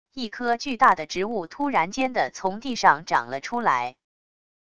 一棵巨大的植物突然间的从地上长了出来wav音频